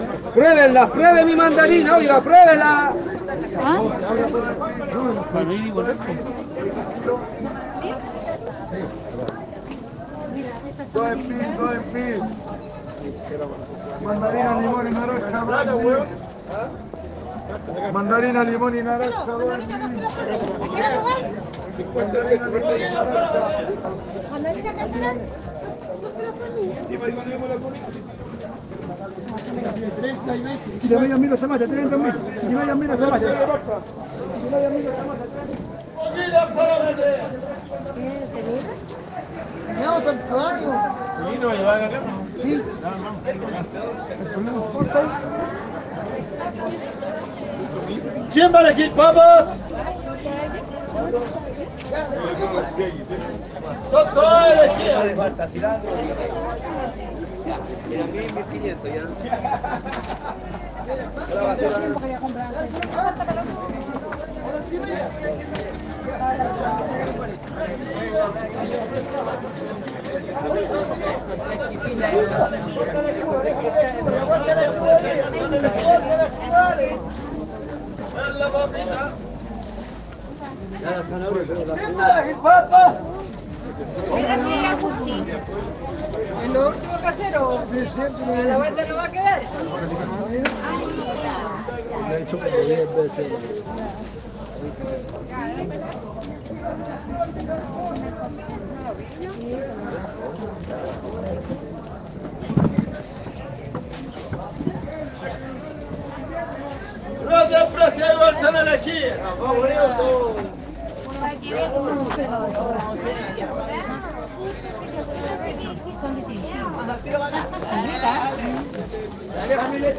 oa-chile-santiago-de-chile-feria-libre-aldunate.mp3